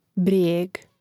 brijȇg brijeg